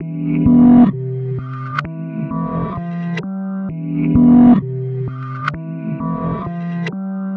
Goosebumps 130 BPM.wav